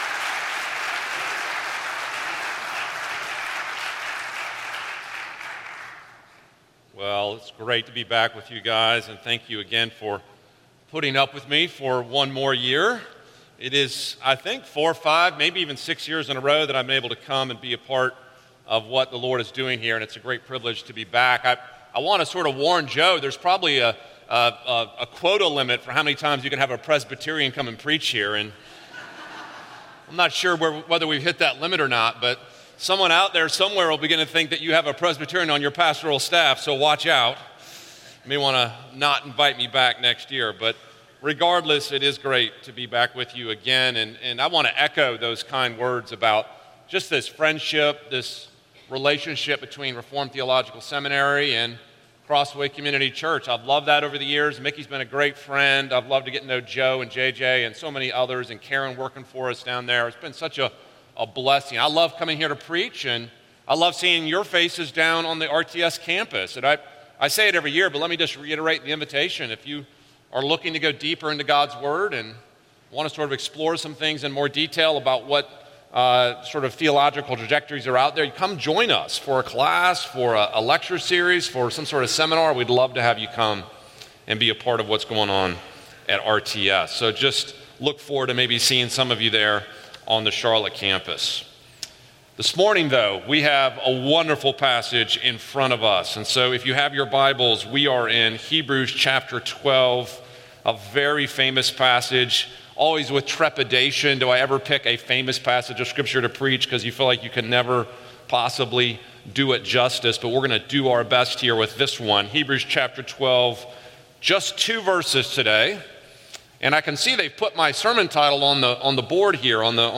The Great Race – Guest Speakers